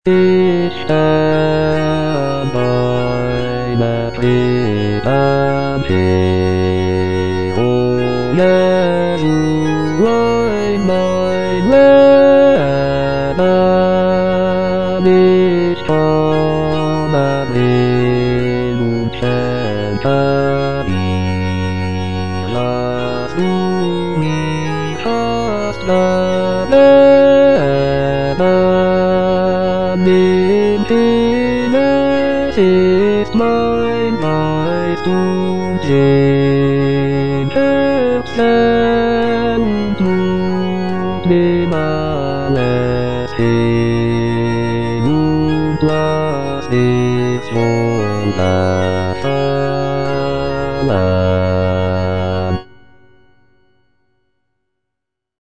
Choralplayer playing Christmas Oratorio BWV248 - Cantata nr. 6 (A = 415 Hz) by J.S. Bach based on the edition Bärenreiter BA 5014a
J.S. BACH - CHRISTMAS ORATORIO BWV248 - CANTATA NR. 6 (A = 415 Hz) 59 - Ich steh an deiner Krippen hier - Bass (Voice with metronome) Ads stop: auto-stop